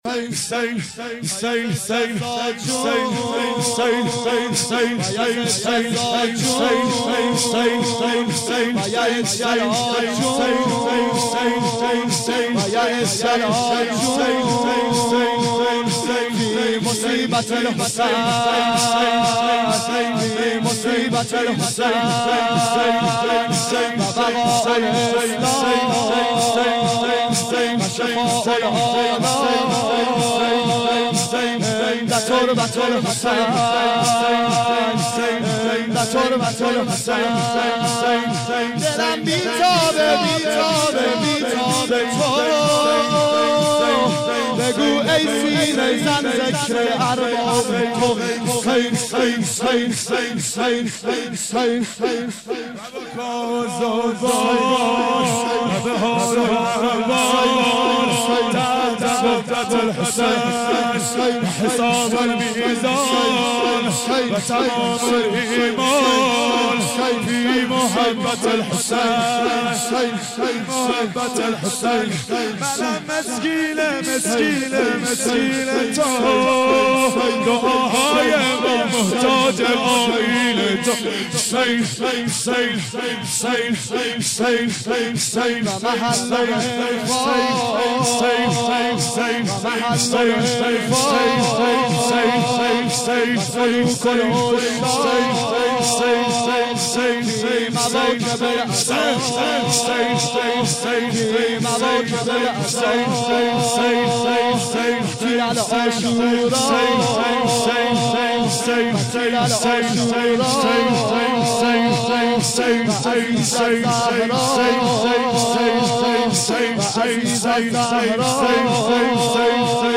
• شور شب پنجم محرم 1391